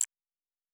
pgs/Assets/Audio/Sci-Fi Sounds/Interface/Digital Click 05.wav at master
Digital Click 05.wav